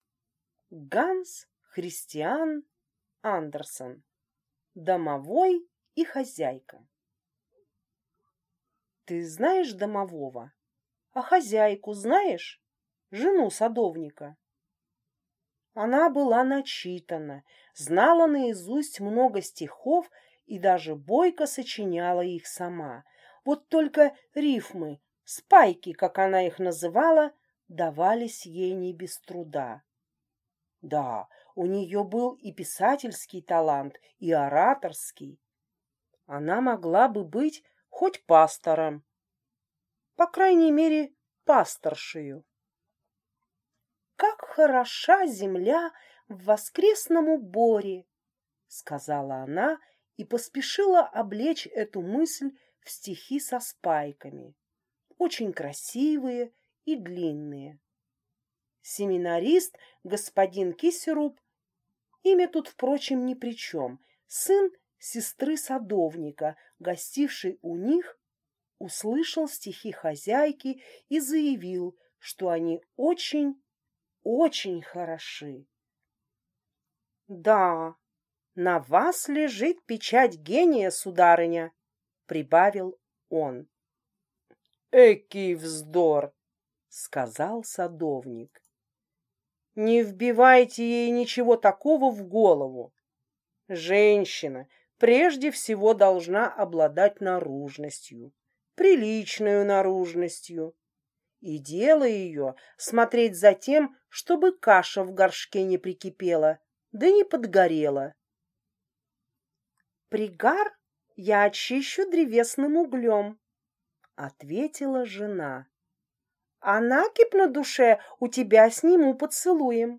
Аудиокнига Домовой и хозяйка | Библиотека аудиокниг